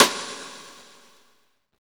SNR XXSTI0NR.wav